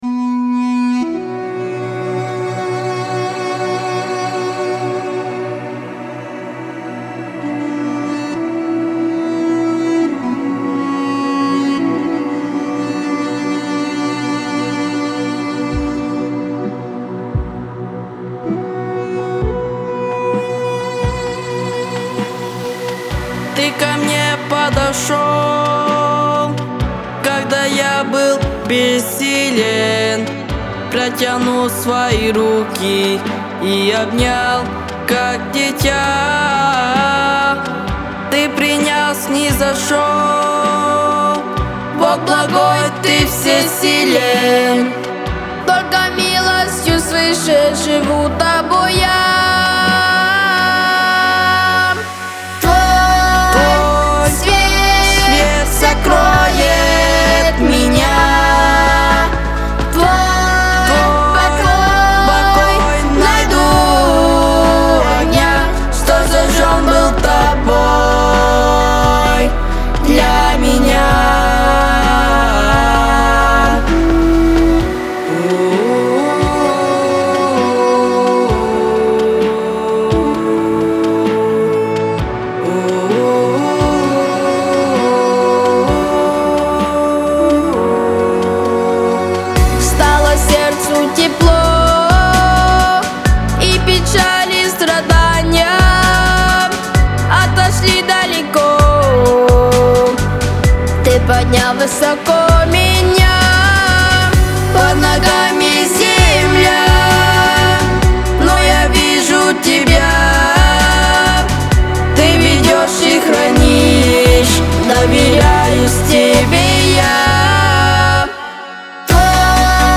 264 просмотра 377 прослушиваний 21 скачиваний BPM: 65